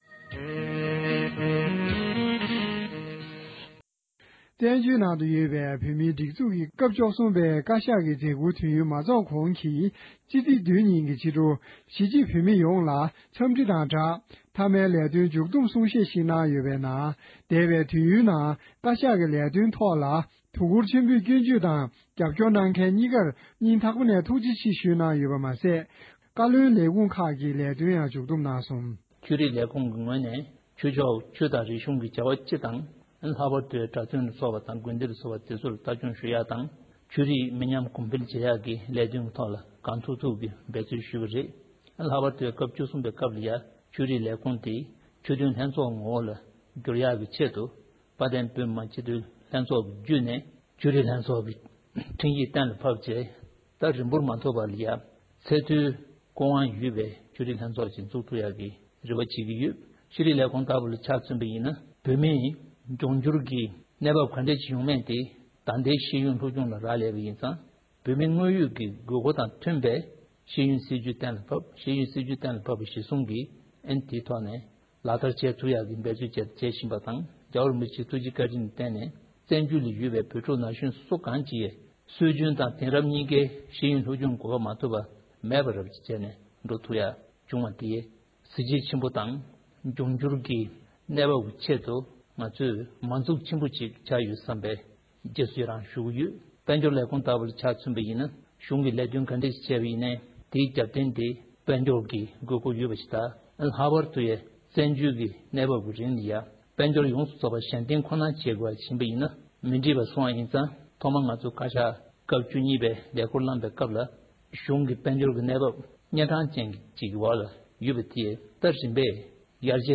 མཇུག་སྡོམ་གསུང་བཤད།